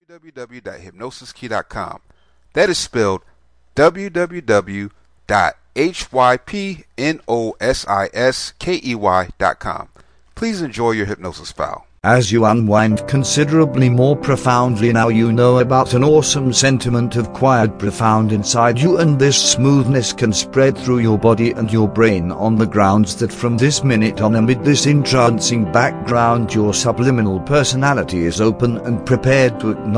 Pancreatis Relaxation Self Hypnosis Mp3
PancreatitisRelaxation.mp3